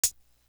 Closed Hats
Crushed Linen Hat.wav